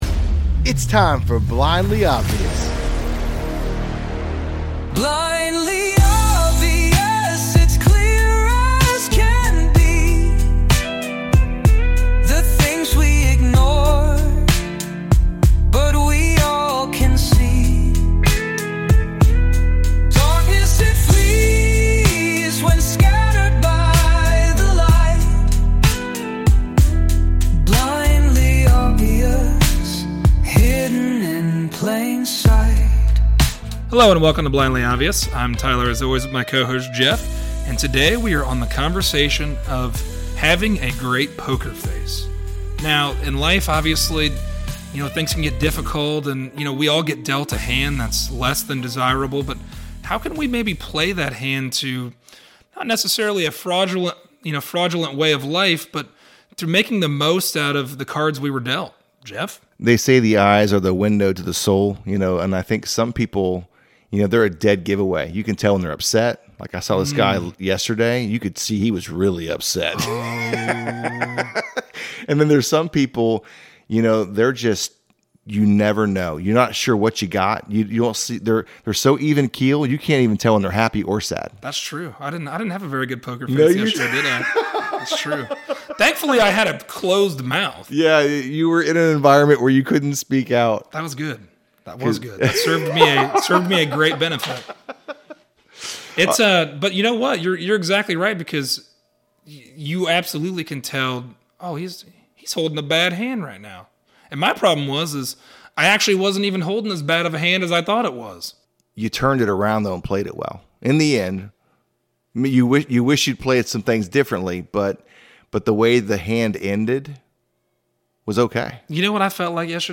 Join us for a conversation on having a good pokerface. When life deals us undesirable cards, how can we present our best face even when we don’t have the best hand to hold?